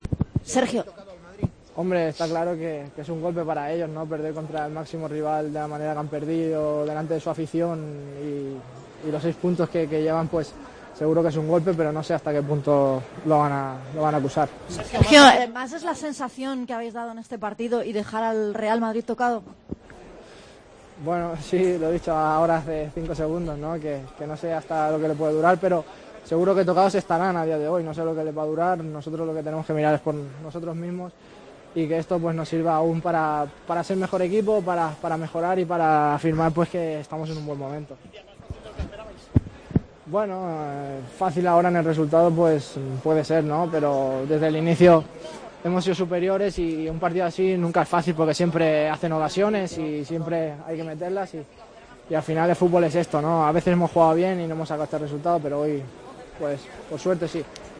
El jugador del Barcelona atendió a los medios de comunicación después del encuentro: "Es un golpe para el rival por la forma de perder. No se sabe de que punto lo van a acusar. Seguro que tocados están. Esto nos tiene que servir para ser mejor equipo. En el resultado, puede parecer fácil, pero estos partidos nunca son fáciles"